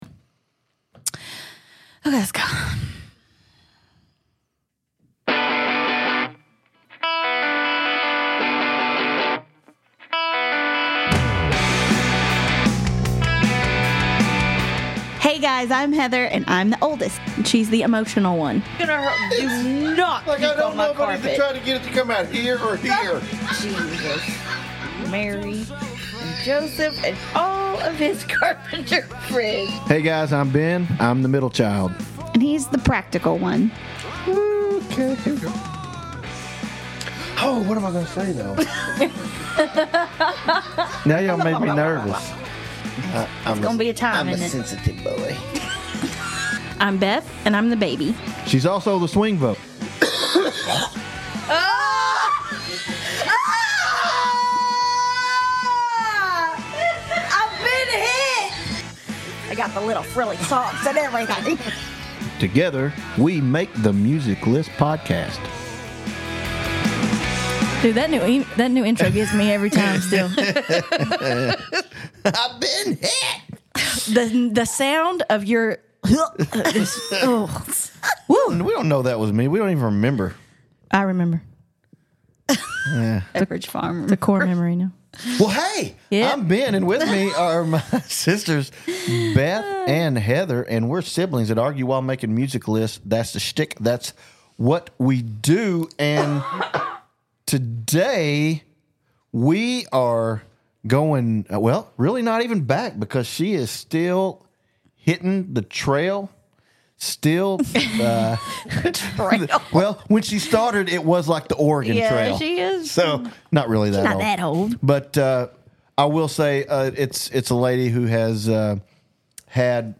So, "Good Luck, Babe," and enjoy this episode dedicated to songs about luck!We also want to apologize for the sound quality during a large portion of this episode.